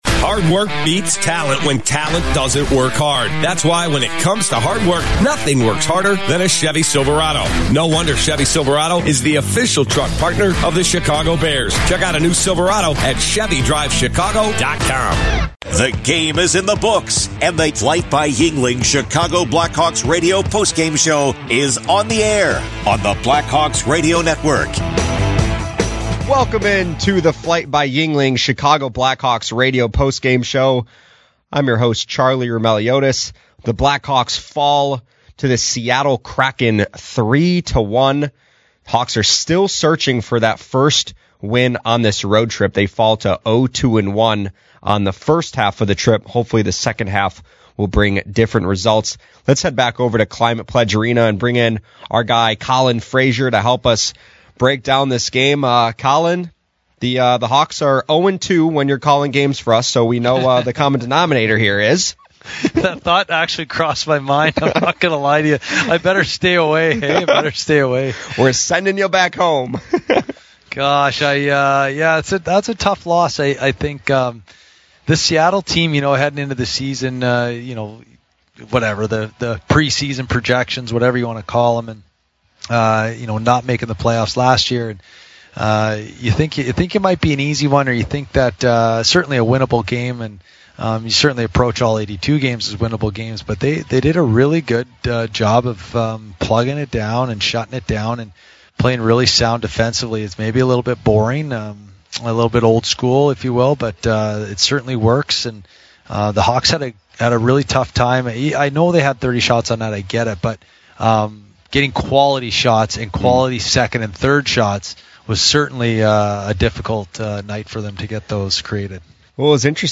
Later, we hear from Burakovsky, Moore and head coach Jeff Blashill.